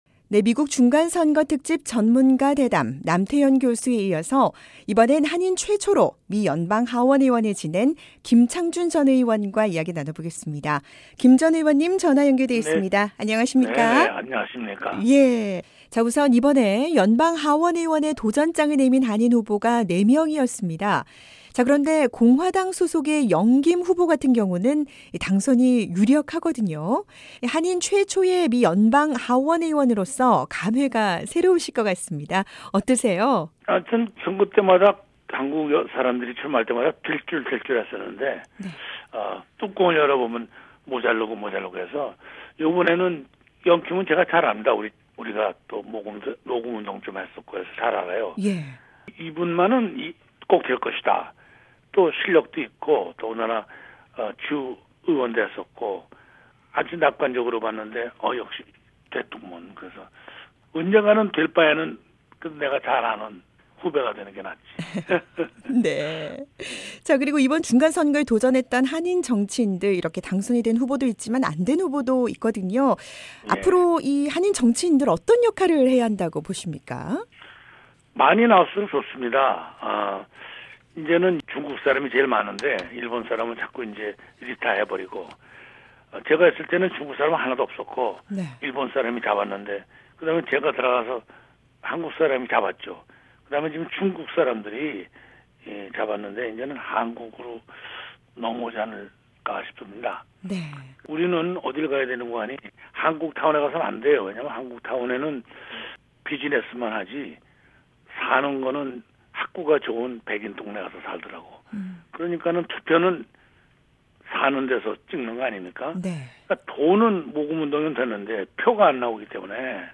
미국 중간선거 특집 방송으로 보내드리고 있는 '생방송 여기는 워싱턴입니다'. 이번엔 한인 최초 연방하원의원을 지낸 김창준 전 의원을 전화로 연결해서 중간선거 결과의 의미와 전망에 대해 들어보겠습니다.